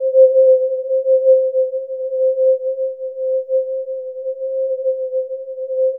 Index of /90_sSampleCDs/Chillout (ambient1&2)/11 Glass Atmos (pad)